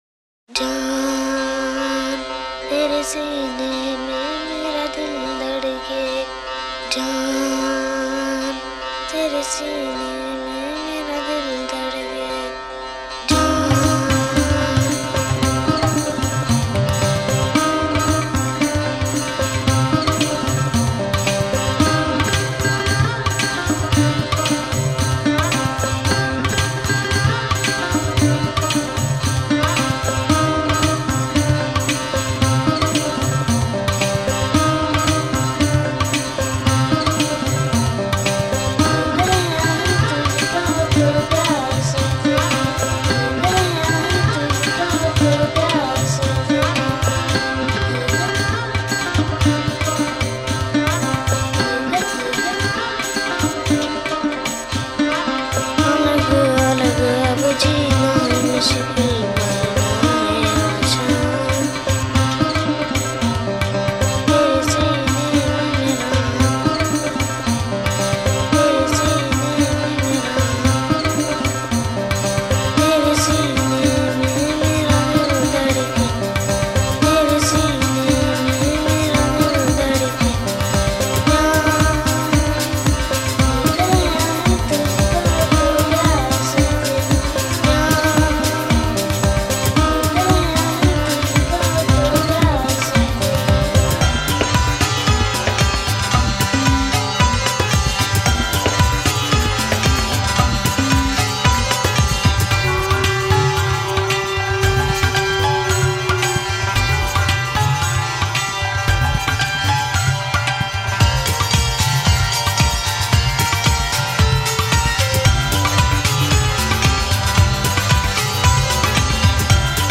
Largo [40-50] amour - percussions - orient - hindou - inde